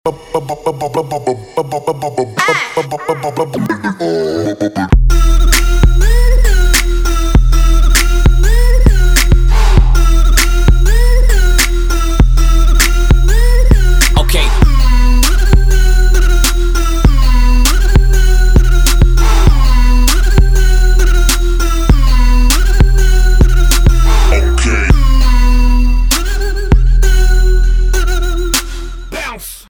• Качество: 192, Stereo
Electronic
без слов
забавный голос
Bass